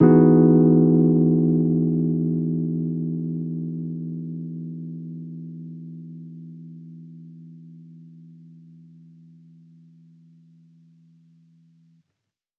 Index of /musicradar/jazz-keys-samples/Chord Hits/Electric Piano 1
JK_ElPiano1_Chord-Emaj9.wav